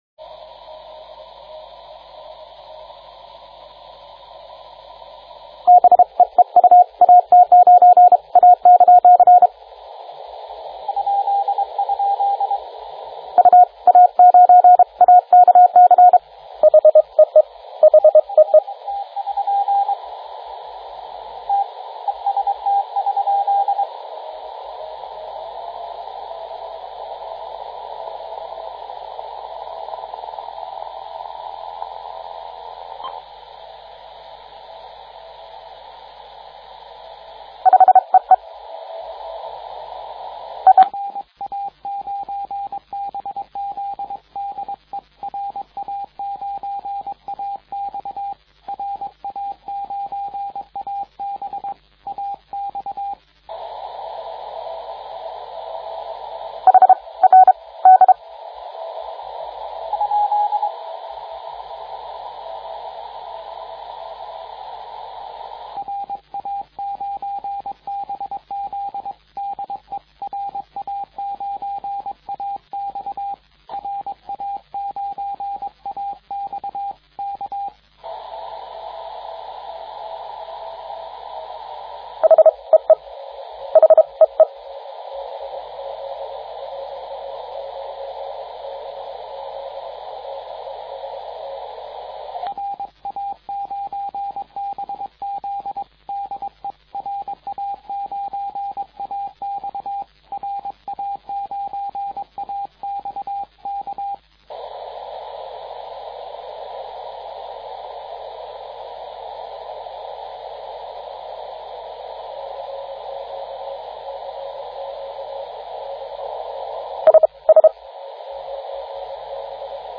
Фрагмент звукового файла (CW), записанного логгером TR4W во время соревнований:
Какой у вас эфир кристально чистый...Ну и кроме дорог есть ещё кое что.
P.S. Аппарат IC-910H, фильтр узкий.